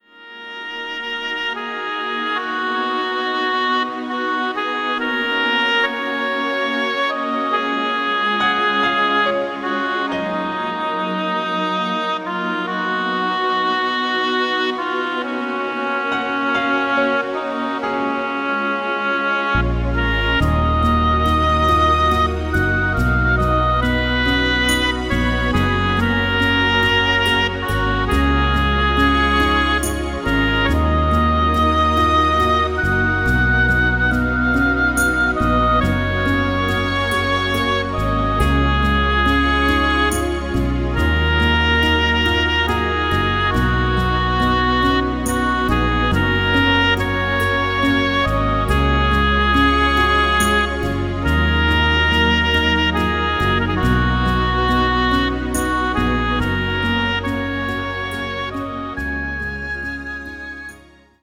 Gefühlvoll arrangierte Instrumentalmusik zum Planschen …